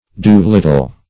Do-little \Do"-lit`tle\, n.